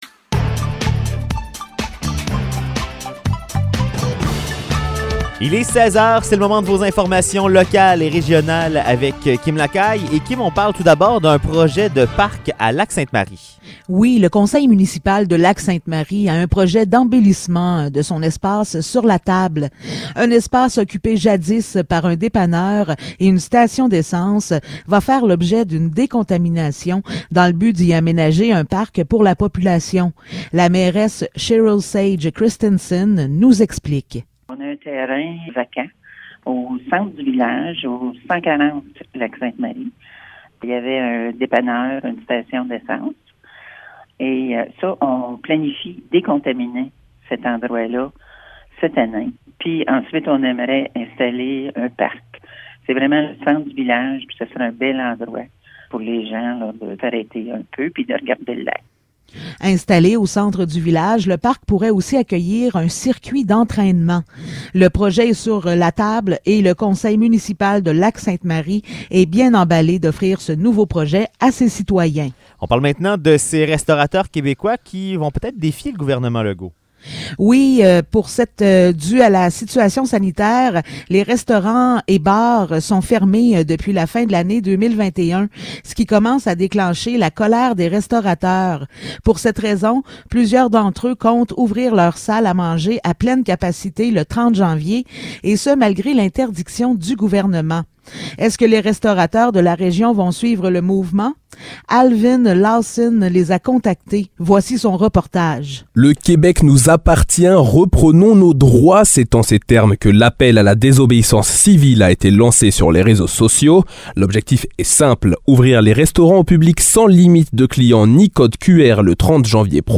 Nouvelles locales - 24 janvier 2022 - 16 h